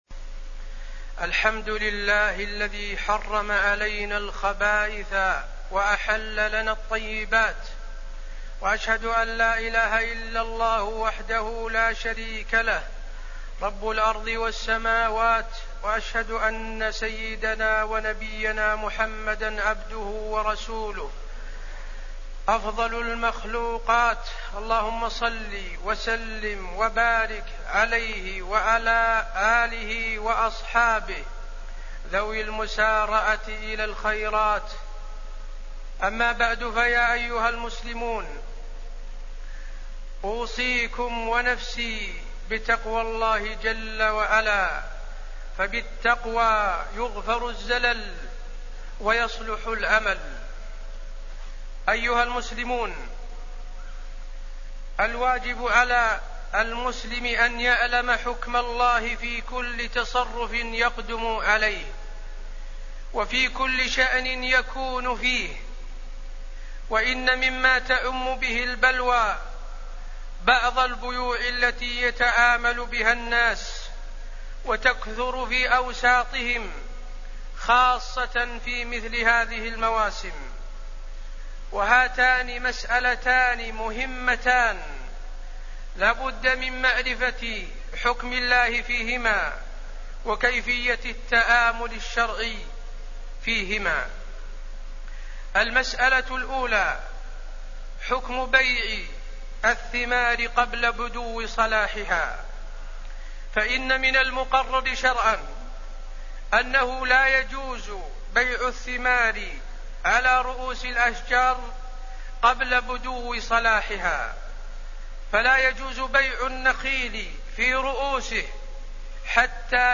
تاريخ النشر ١٤ ربيع الثاني ١٤٣٠ هـ المكان: المسجد النبوي الشيخ: فضيلة الشيخ د. حسين بن عبدالعزيز آل الشيخ فضيلة الشيخ د. حسين بن عبدالعزيز آل الشيخ البيوع